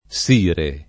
sire [ S& re ]